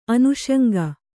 ♪ anuṣaŋga